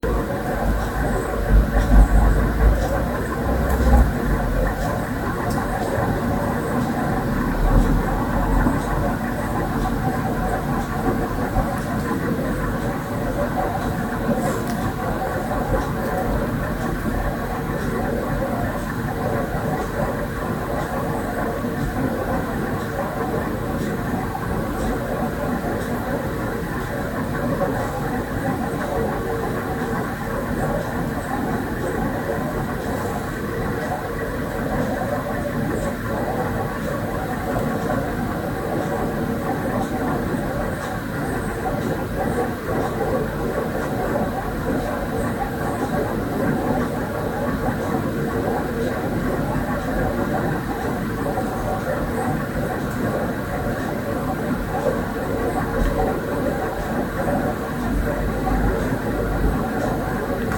Bruit d'écoulement d'eau dans les canalisations.
Et ci-joint l'enregistrement du bruit de la canalisation intérieure sad
Pour le 2ème mp3, j'ai enregistré avec un téléphone (de bonne qualité) et normalisé le son.